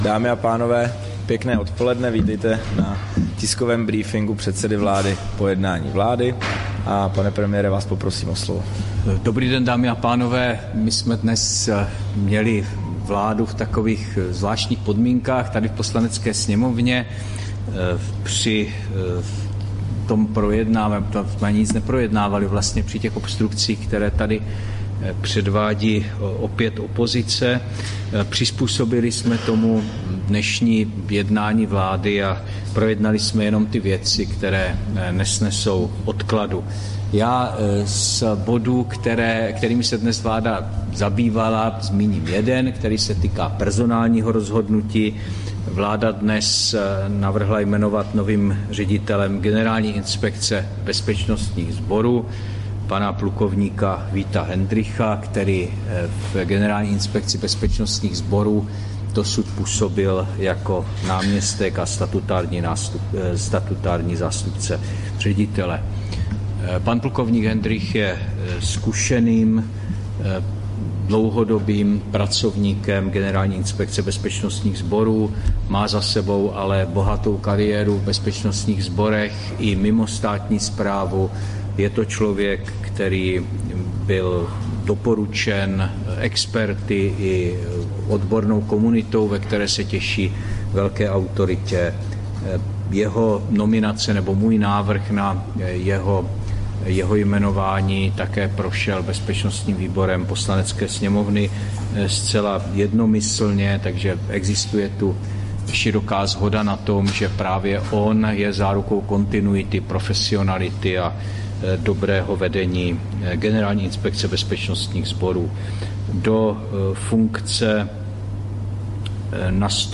Tisková konference po jednání vlády, 6. září 2023